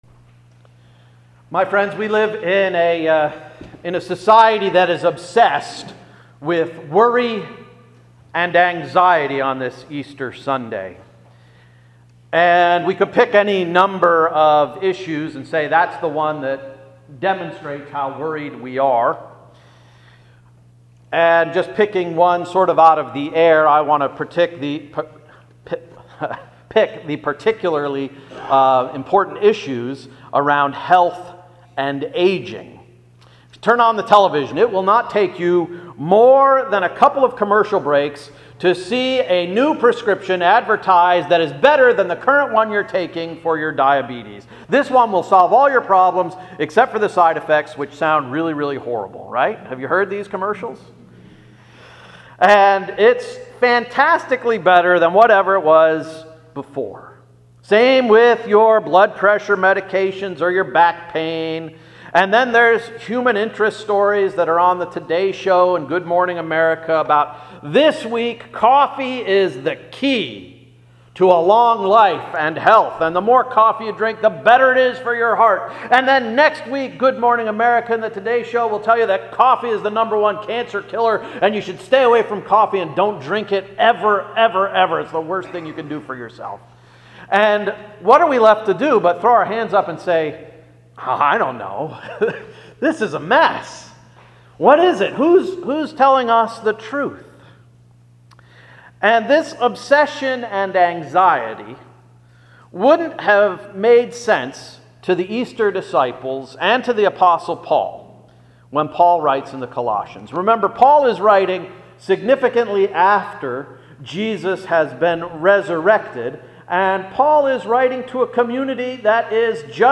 April 16, 2017 Sermon — “What next?”